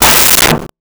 Metal Strike 06
Metal Strike 06.wav